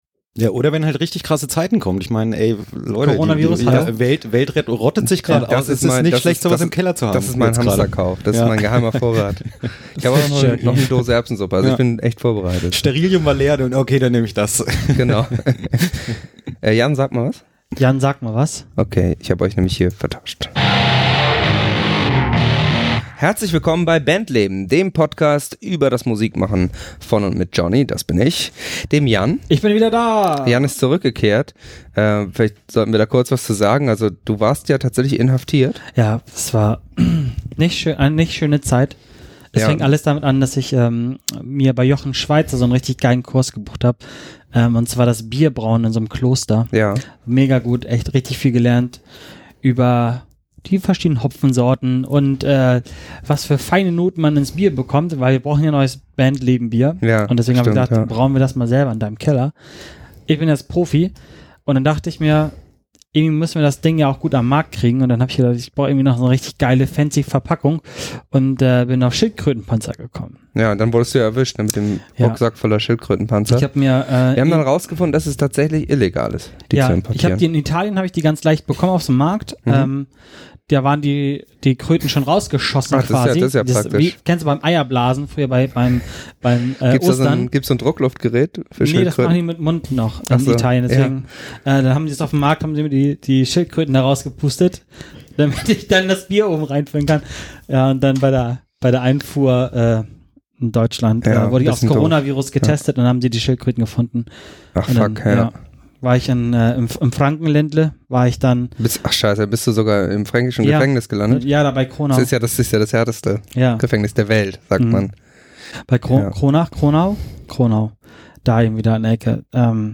Drummertalk